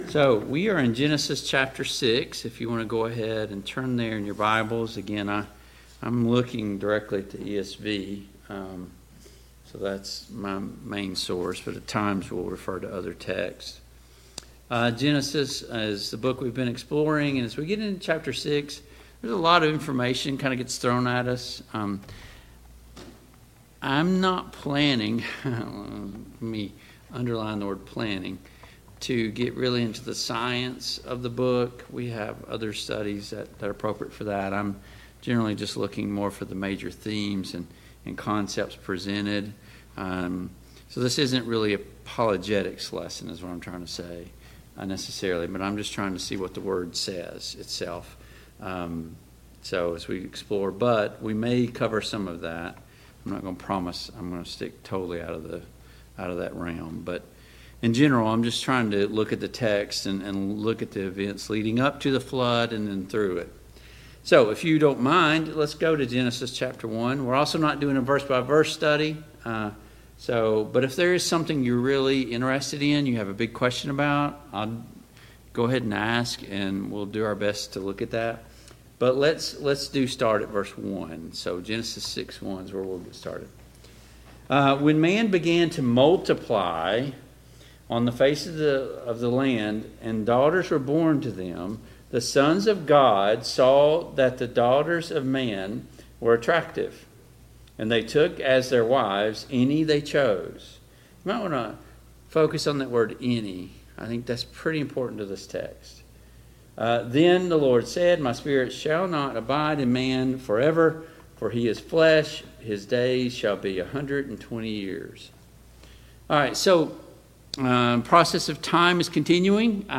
Genesis 6 Service Type: Family Bible Hour Topics: Noah and the Flood , The Flood « Paul and the Ephesian church 17.